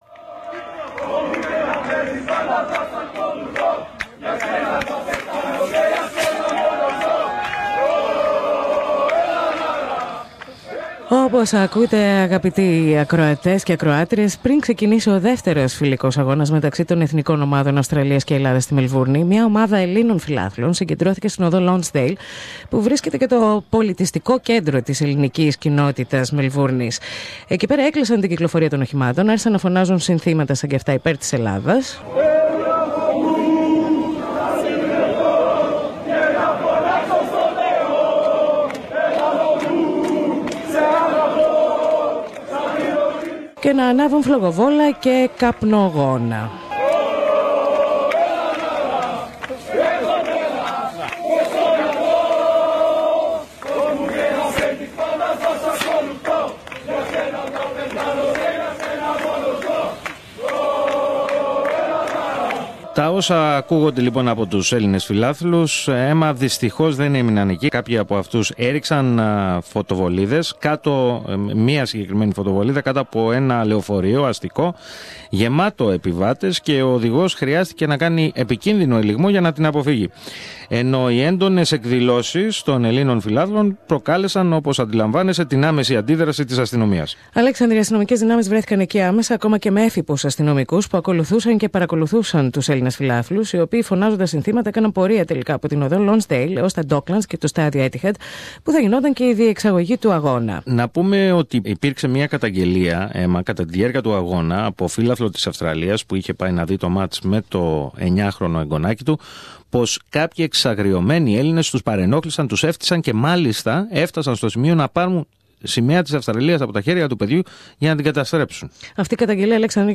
Ρεπορτάζ